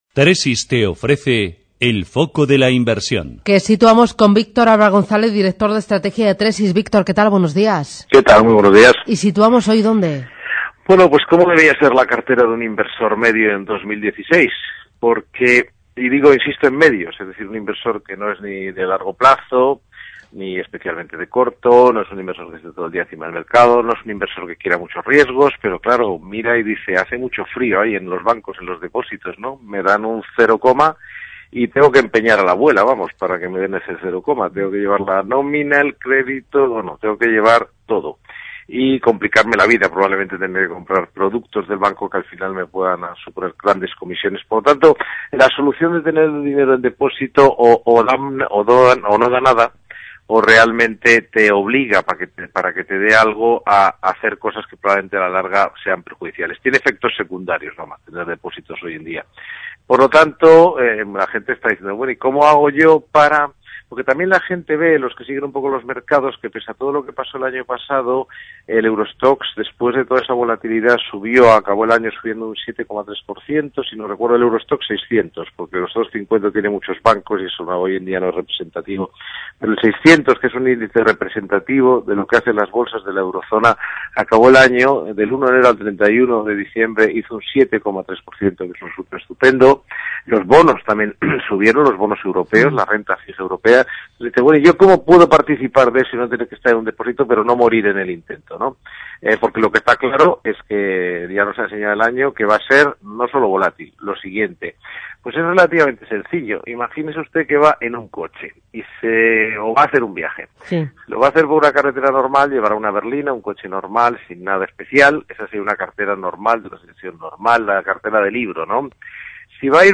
En radio intereconomia todas las mañanas nuestros expertos analizarán la actualidad de los mercados.